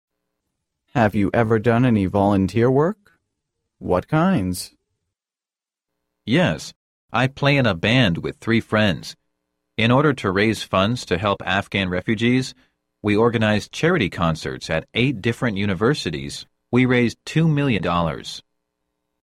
真人朗读，帮助面试者迅速有效优化面试英语所需知识，提高口语能力。